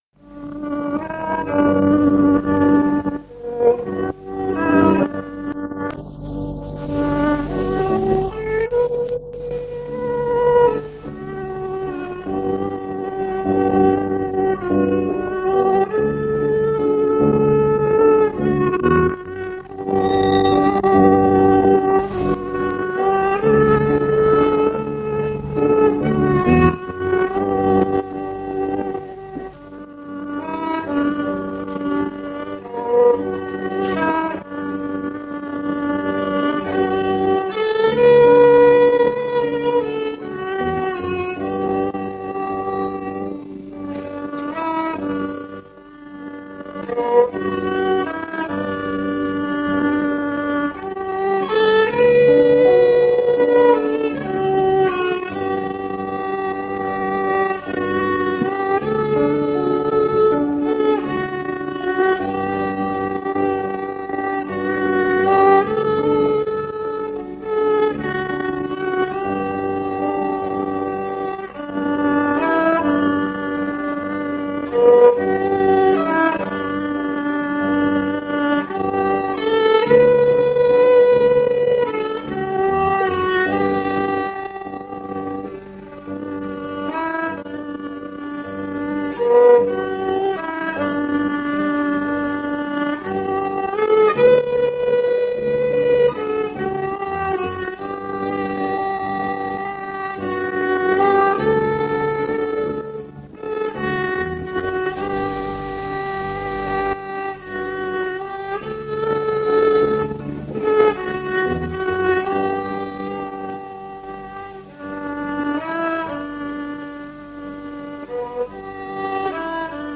Jutlus vanalt lintmaki lindilt 1978 aasta kevadel.